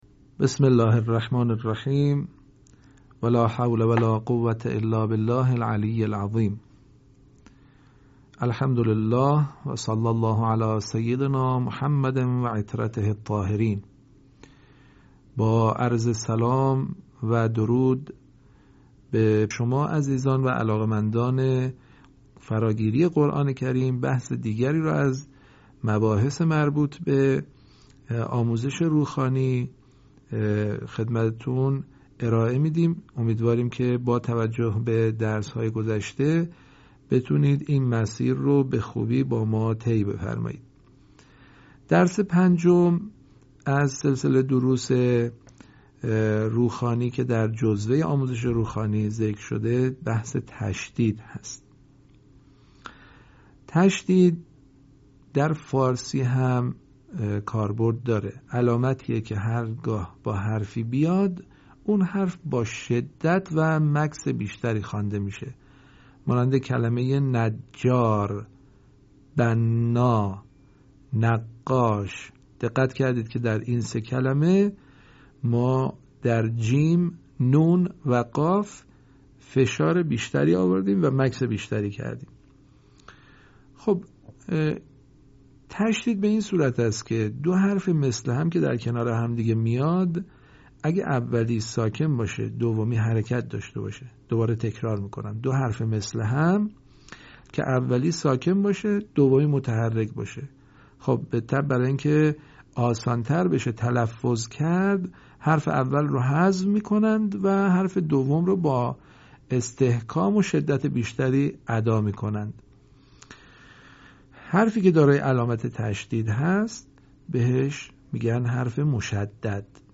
صوت | آموزش نحوه تلفظ واو ساکن ما قبل مفتوح